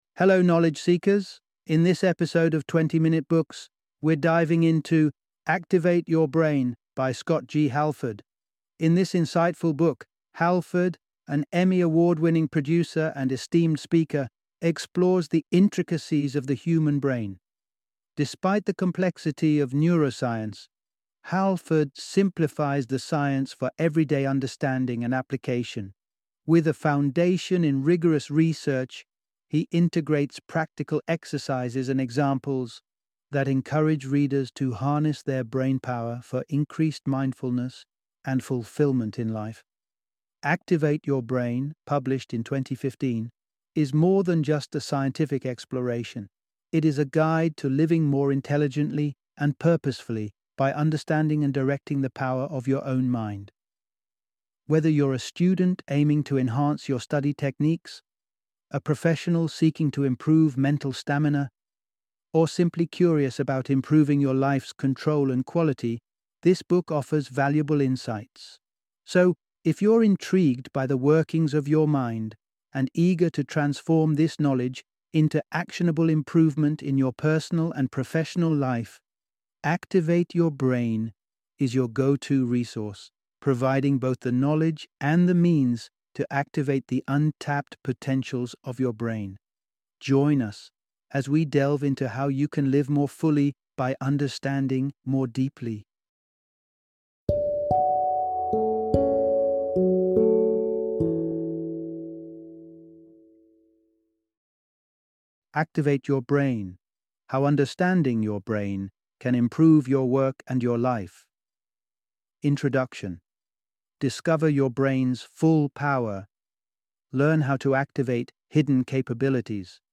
Activate Your Brain - Audiobook Summary
Activate Your Brain - Book Summary